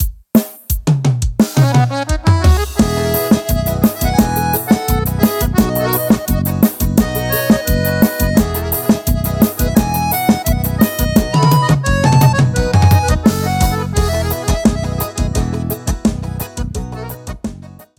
• Demonstrativo Vaneira:
• São todos gravados em Estúdio Profissional, Qualidade 100%